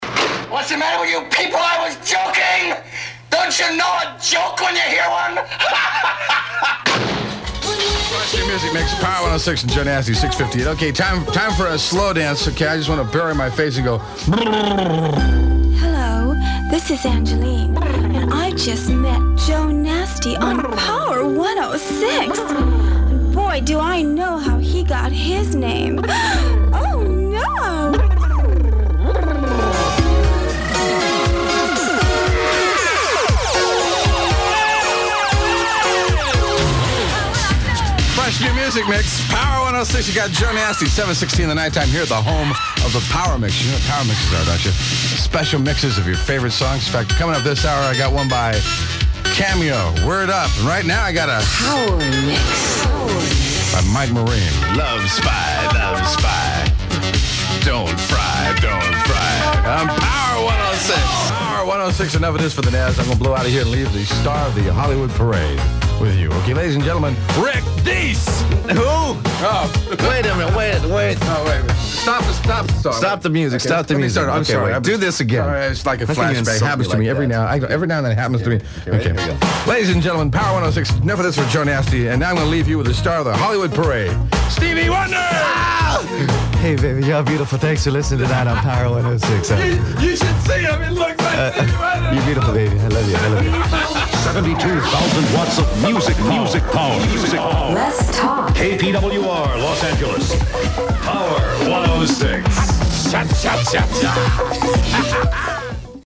Partner Demos